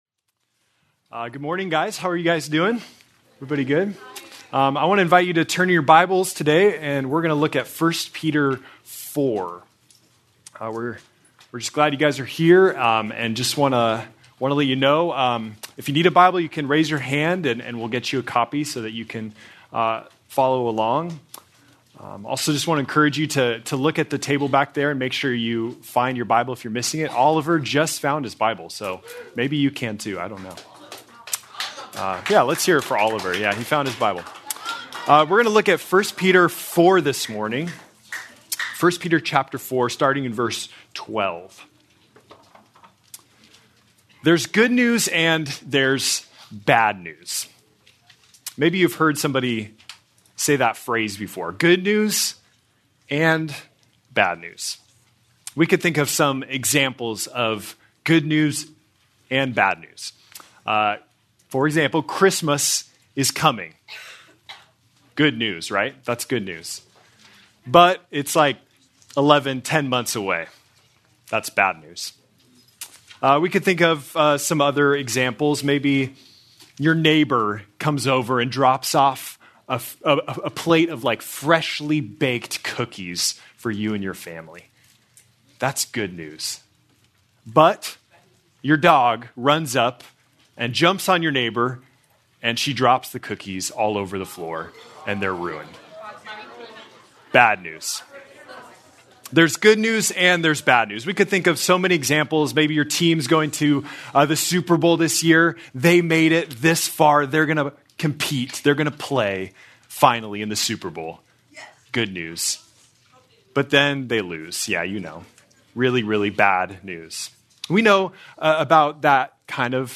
February 8, 2026 - Sermon
Please note, due to technical difficulties, this recording skips brief portions of audio.